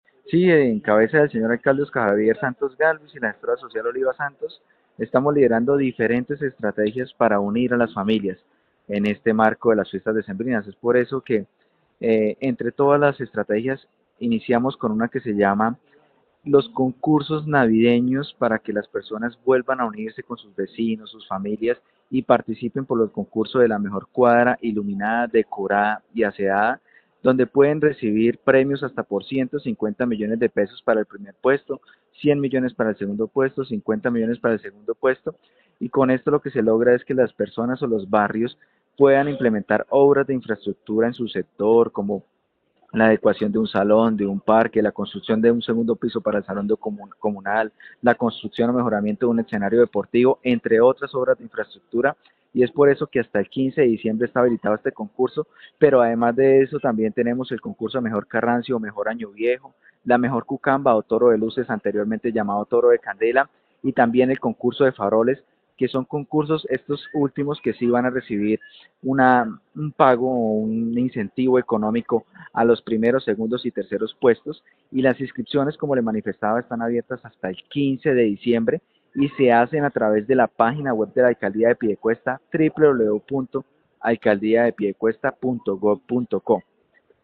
Jeferson Osorio, director de Cultura en Piedecuesta